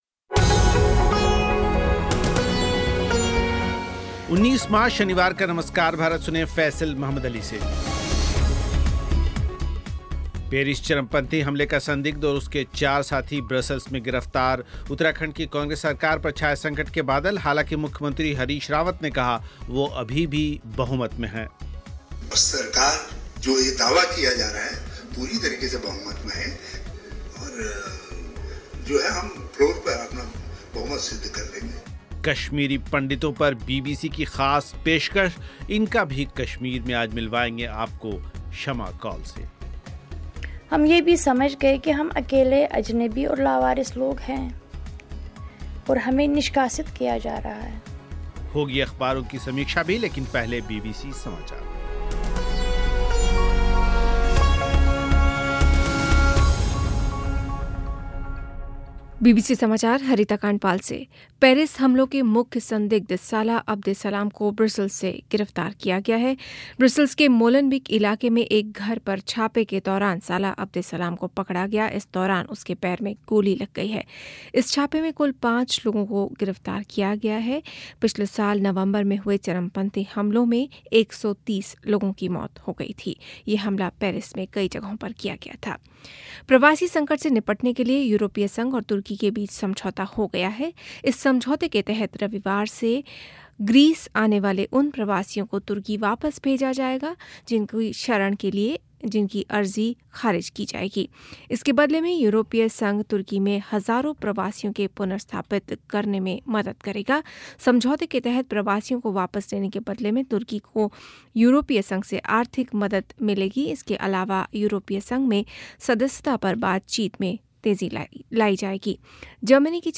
पेरिस चरमपंथी हमले का संदिग्ध और उसके चार साथी ब्रसेल्स में गिरफ्तार उत्तराखंड की कांग्रेस सरकार पर छाए संकट के बादल, सुनें एक रिपोर्ट
मुख्यमंत्री हरीश रावत के प्रेस कांफ्रेस के कुछ हिस्से